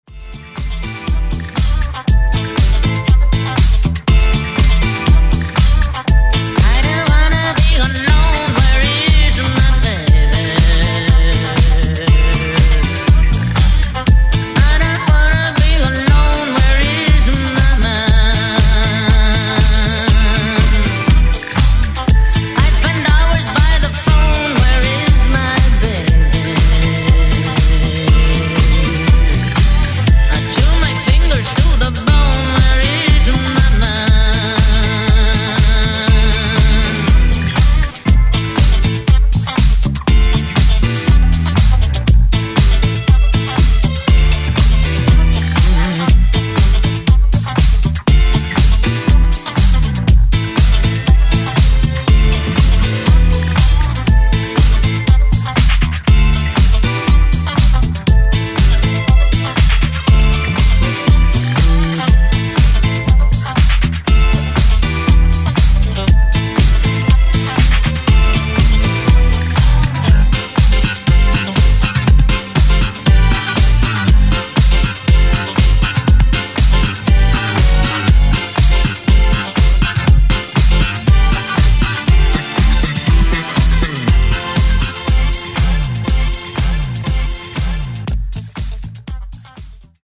Genre: 80's Version: Clean BPM: 120 Time